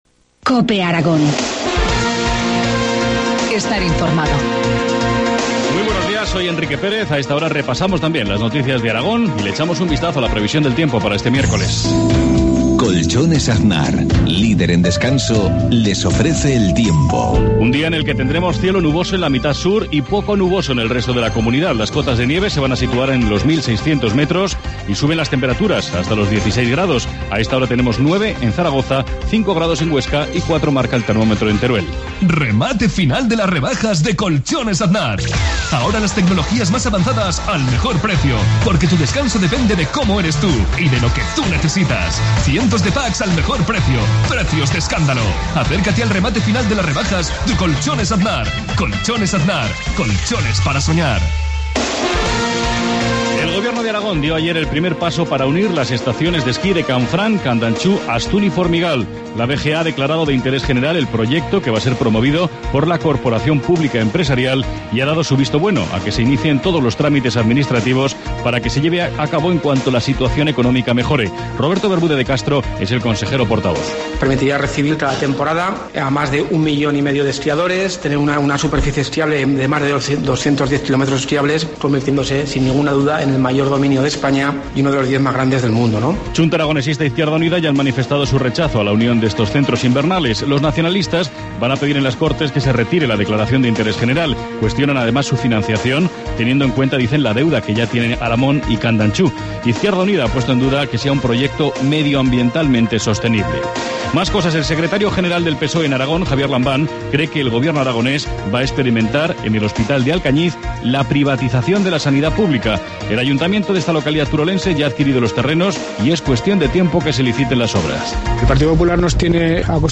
Informativo matinal, miércoles 20 de febrero, 7.53 horas